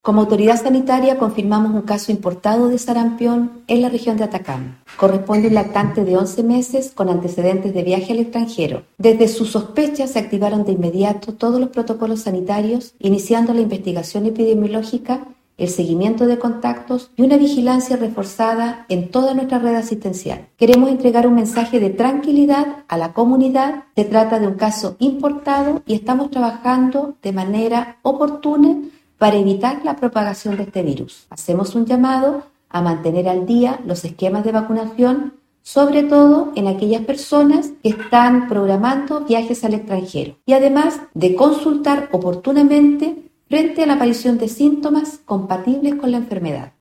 Seremi-Jessica-Rojas.mp3